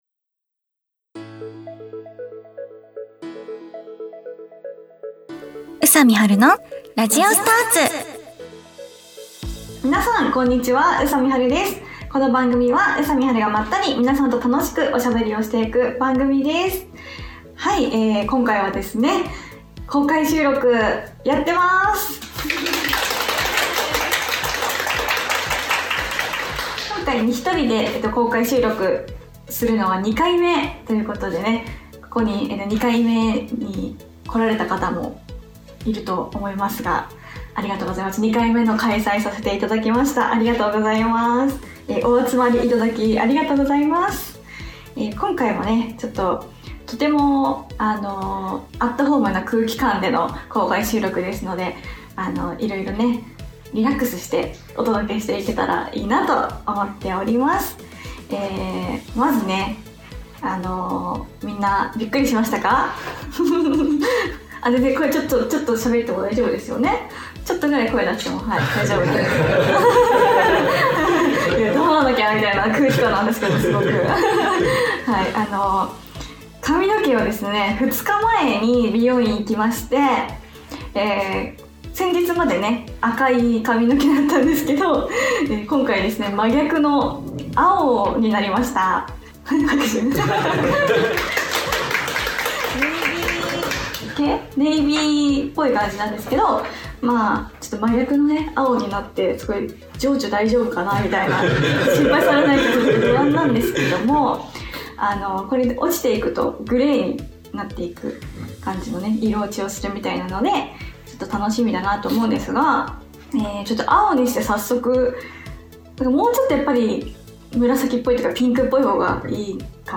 約8ヶ月ぶりに公開収録が開催されました！